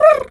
pgs/Assets/Audio/Animal_Impersonations/pigeon_2_stress_05.wav
pigeon_2_stress_05.wav